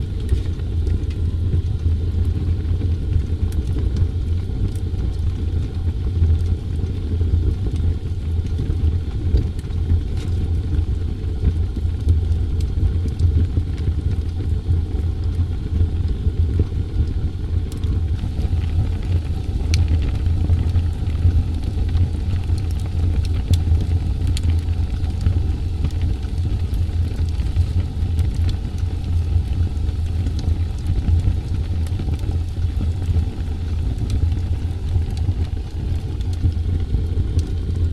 ambientFire2.ogg